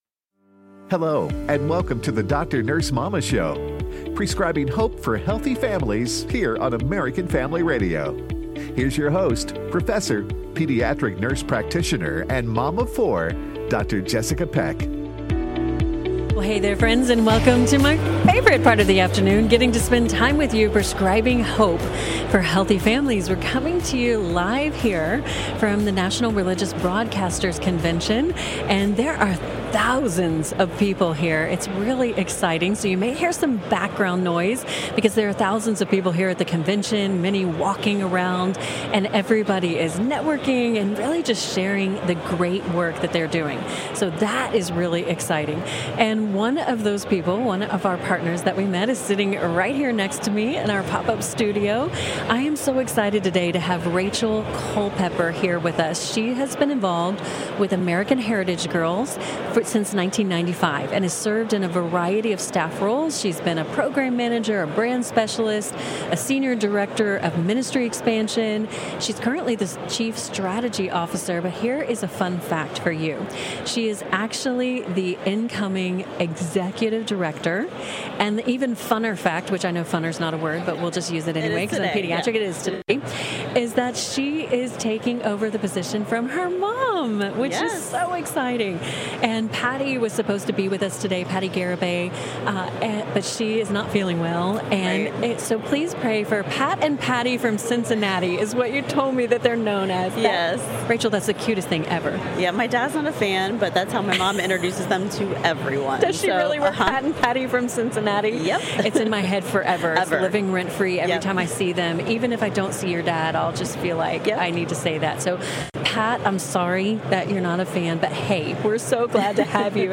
Live from NRB.